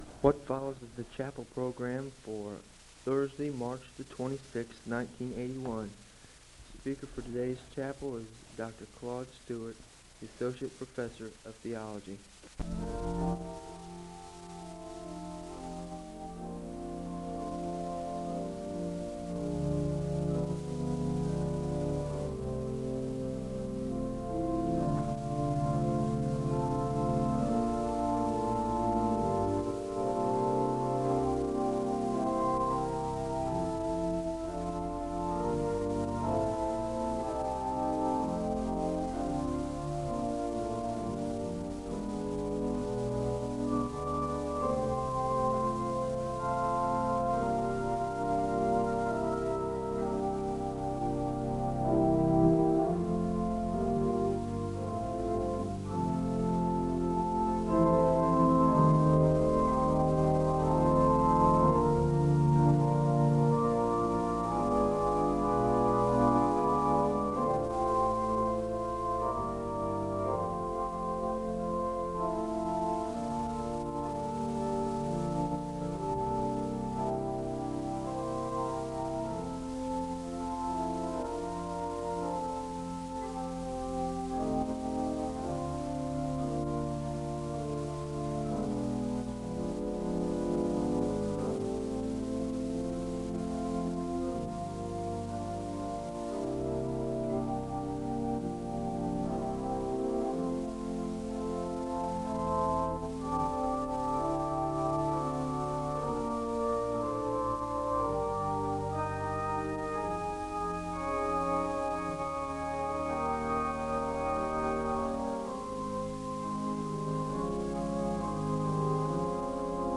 The service begins with organ music (00:00-04:08). The speaker gives a word of prayer (04:09-05:37). The choir sings a song of worship (05:38-09:40).
Lenten sermons